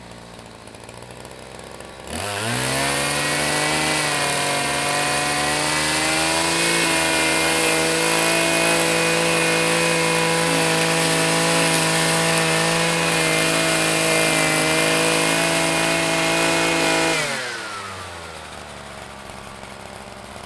• grass trimmer 6.wav
grass_trimmer_6_BZF.wav